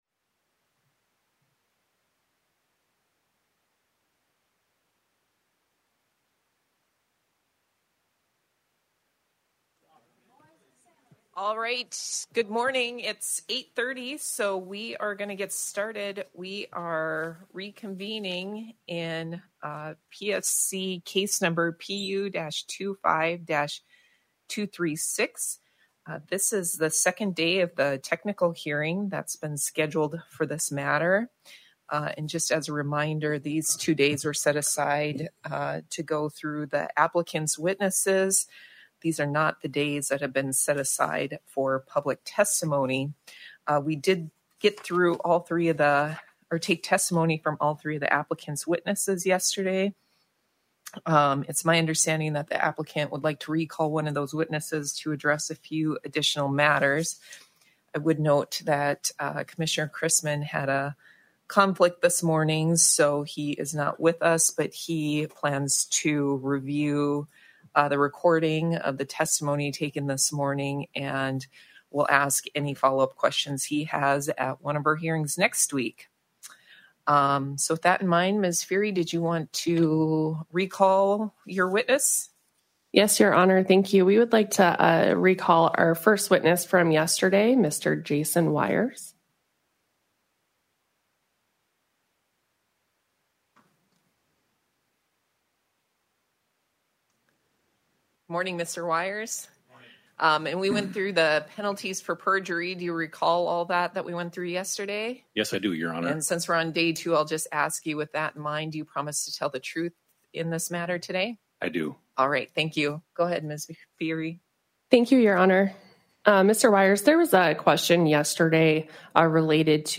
Electronic Recording of 09 January 2026 Formal Hearing